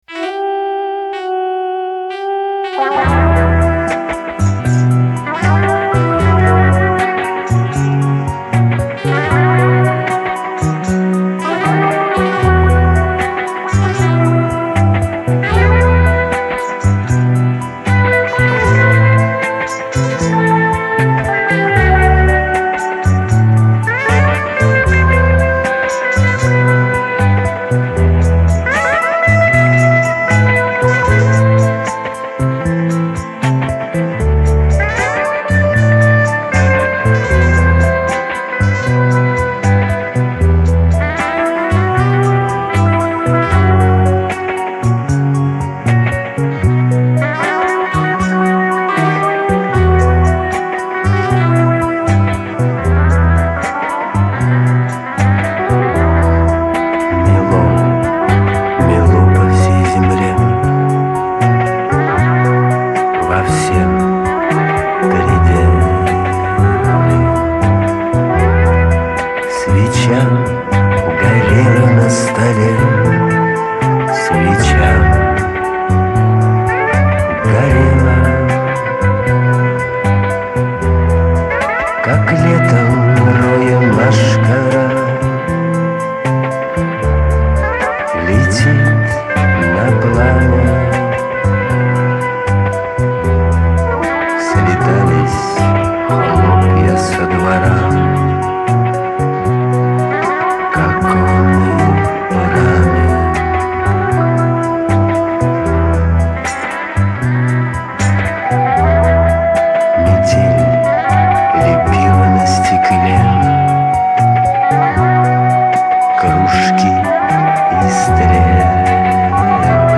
Позвольте вам предложить шансонный вариант Пастернака-для прослушивания...И не грустите!Все проходит-пройдет и это...mail